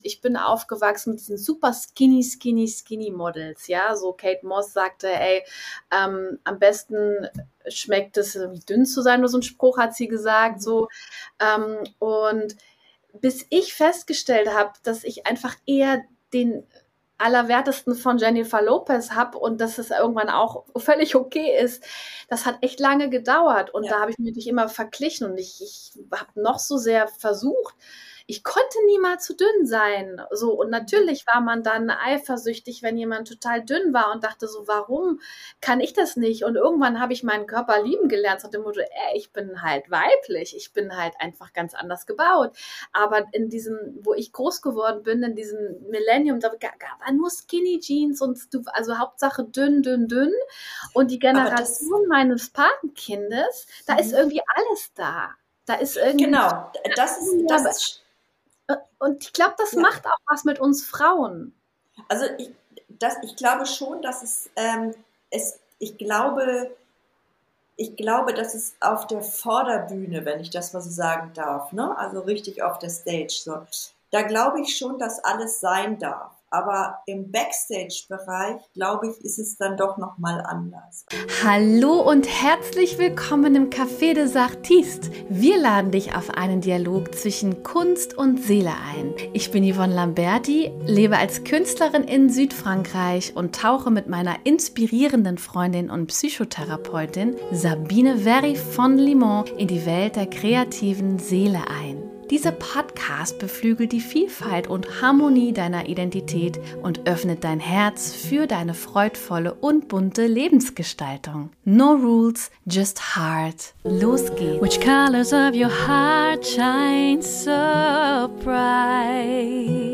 Ein tiefes Gespräch über Neid, Selbstzweifel, Frauenbilder, Schwesternschaft und die Kunst, sich in einer lauten Welt innerlich treu zu bleiben.